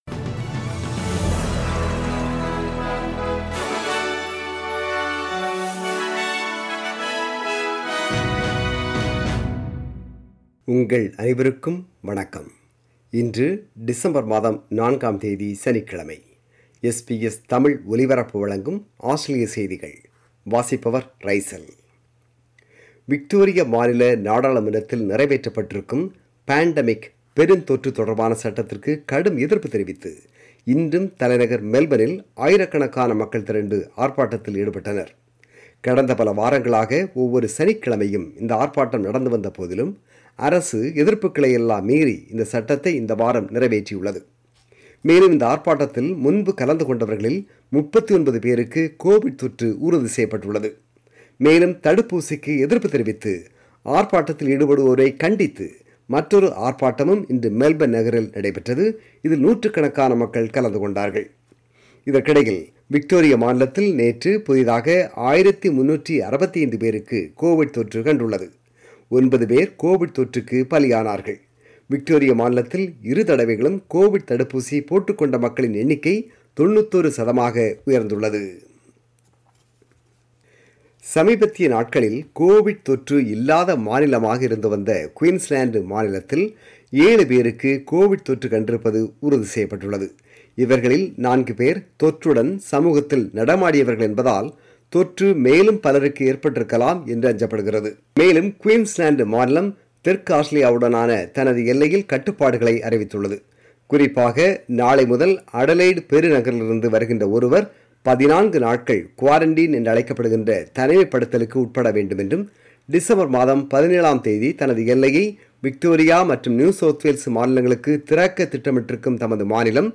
Australian News: 4 December 2021 – Saturday